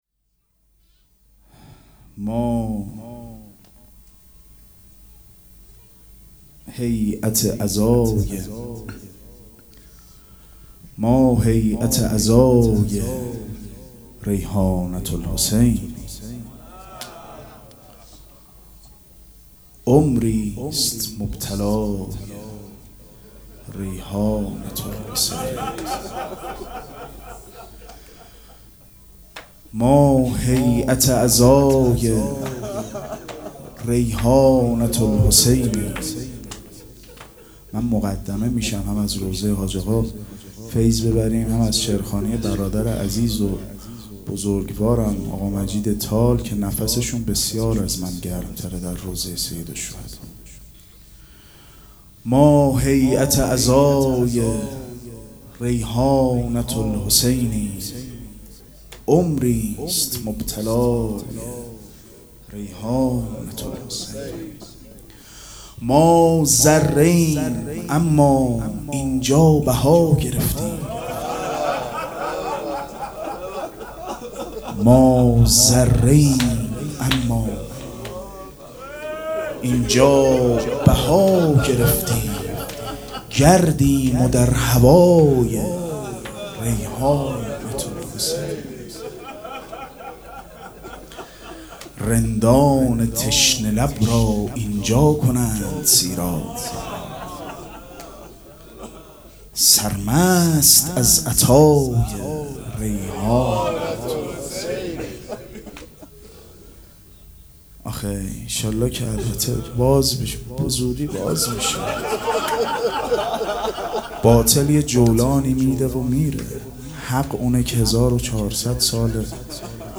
مراسم عزاداری شب شهادت حضرت رقیه سلام الله علیها
شعر خوانی